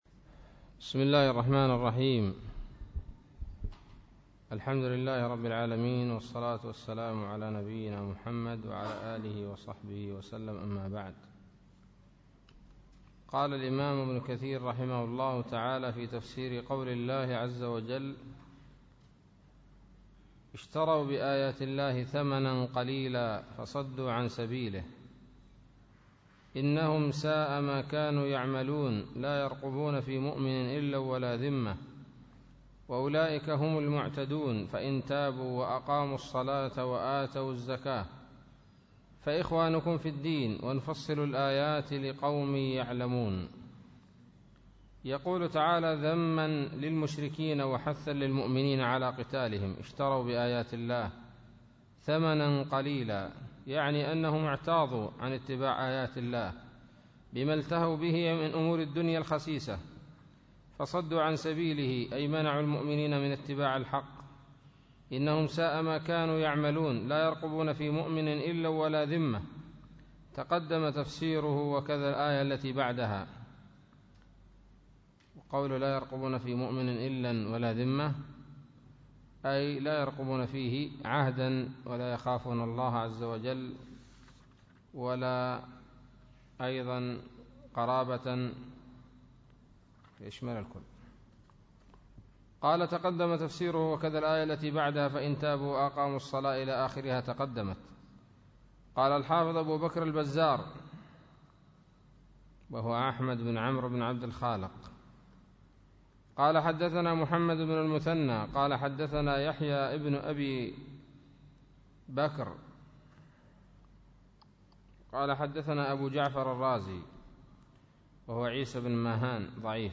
الدرس السابع من سورة التوبة من تفسير ابن كثير رحمه الله تعالى